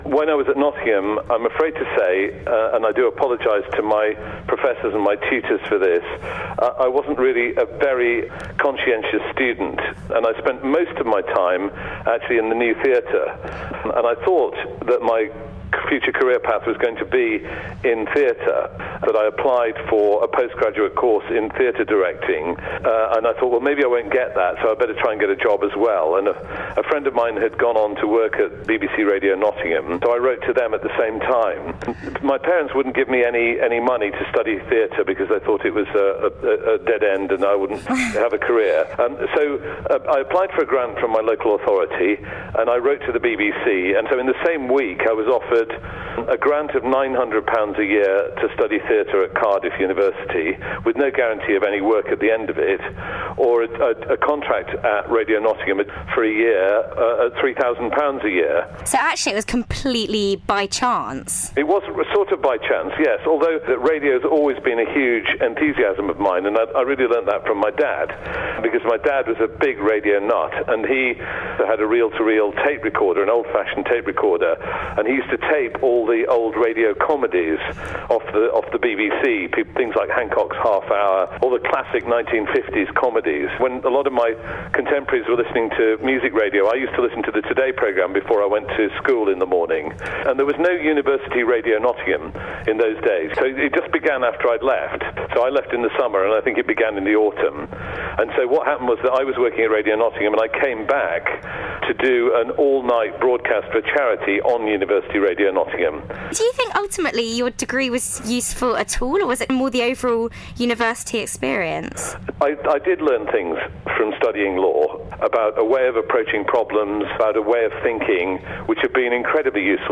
URN Interviews Matthew Bannister